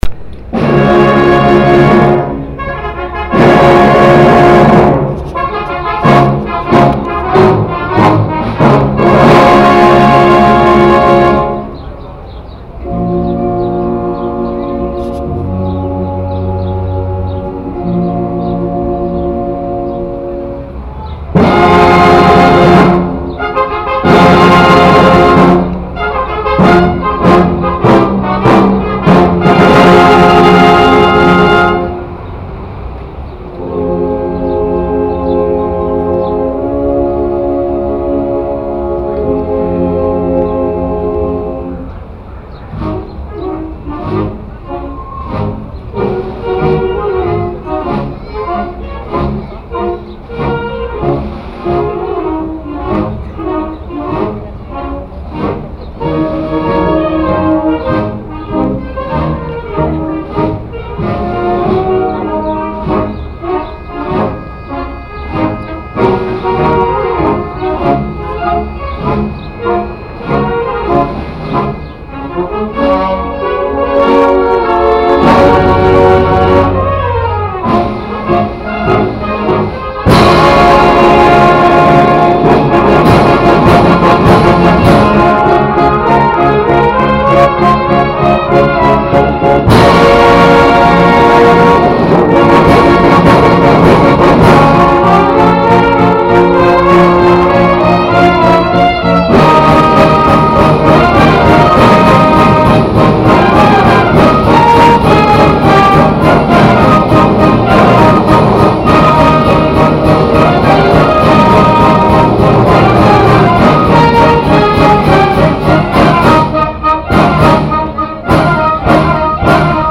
Concert a Santiago, plaza de Armas.MP3